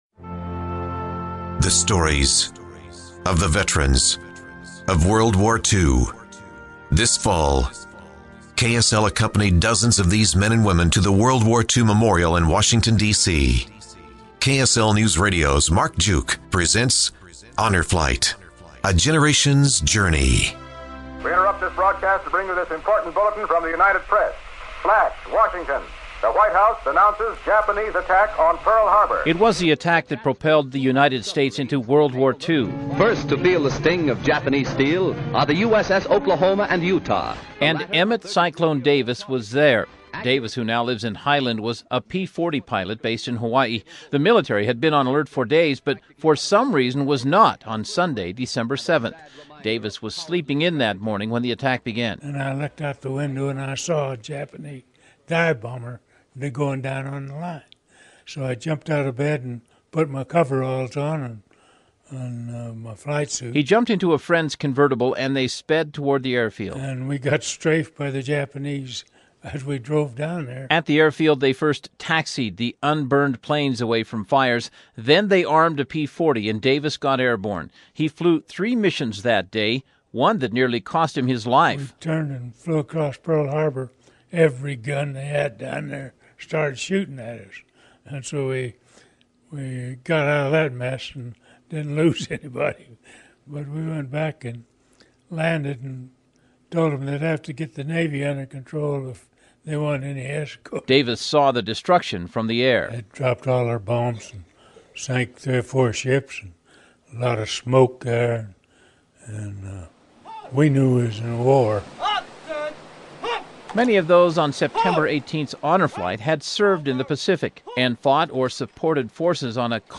Part 3: Utah Honor Flight / KSL Newsradio documentary